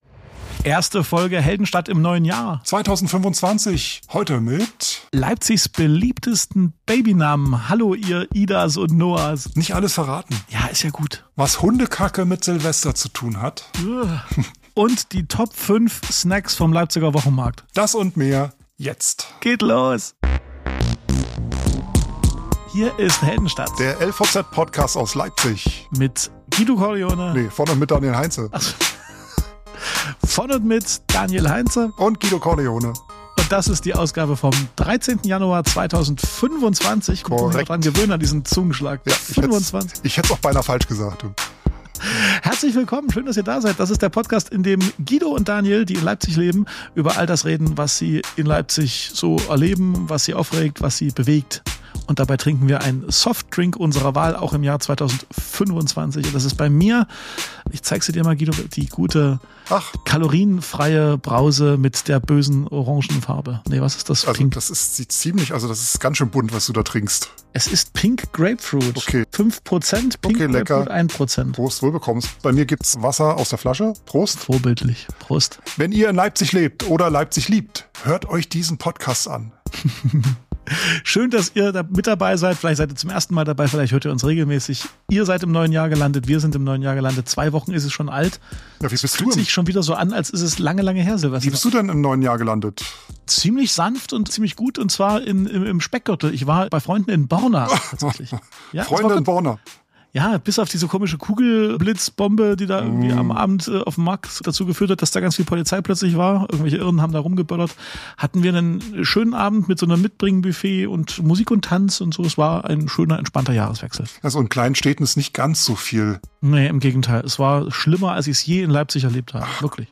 Statt Glühwein gibt's heute das entspannte Knistern vom Kamin und ein eigens komponiertes Weihnachts…